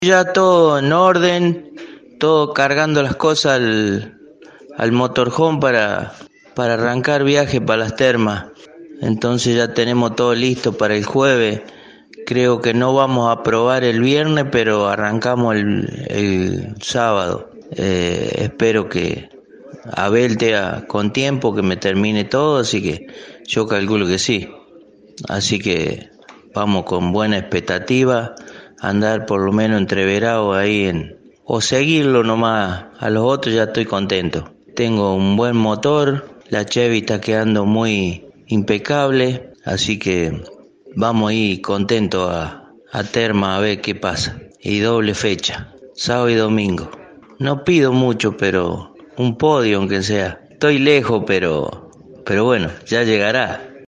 En diálogo con Poleman Radio, palpitó lo que viene: